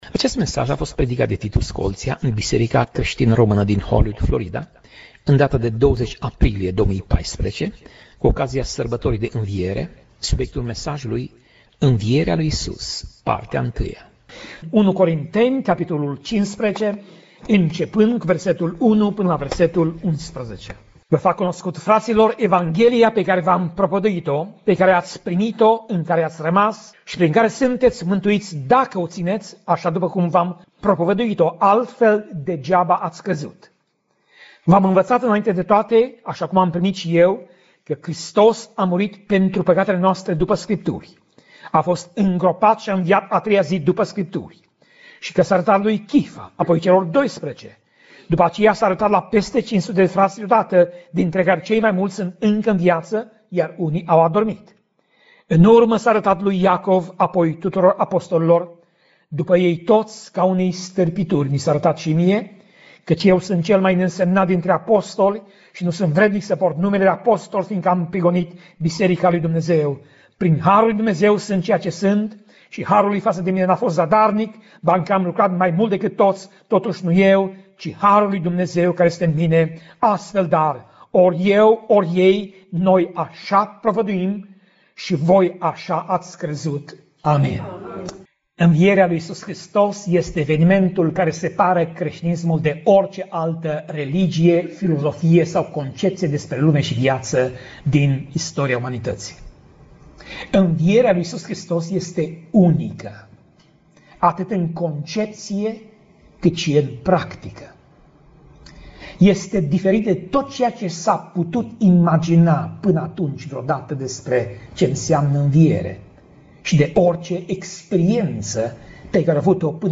Pasaj Biblie: 1 Corinteni 15:1 - 1 Corinteni 15:11 Tip Mesaj: Predica